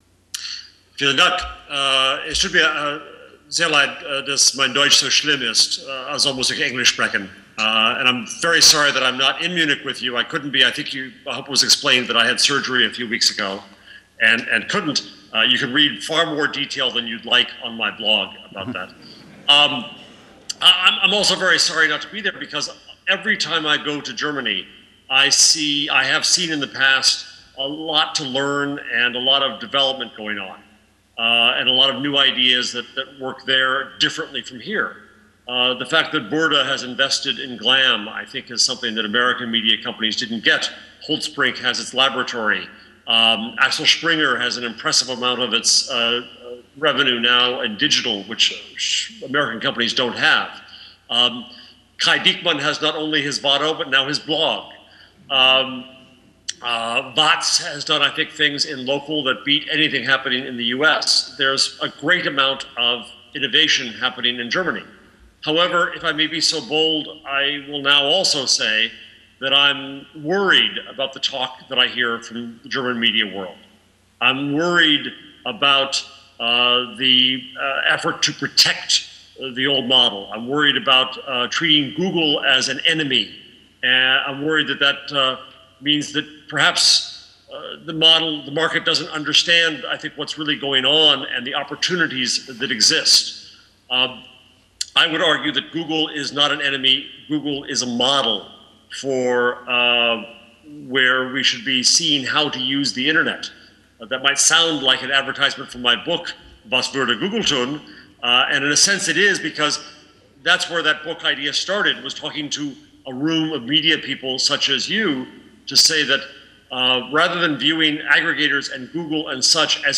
Update: Hier ist die Keynote noch einmal als MP3.